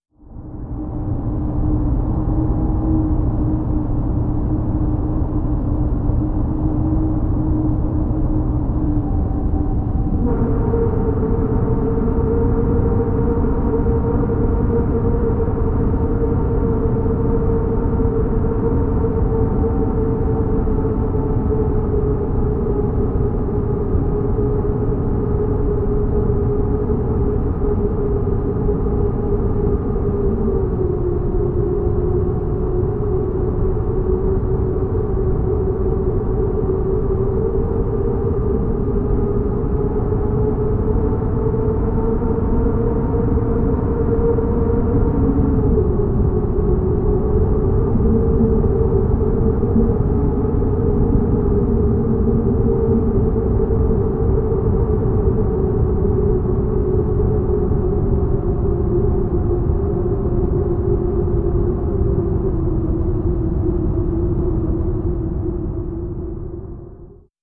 Звук запаха, наполняющий заброшенный дом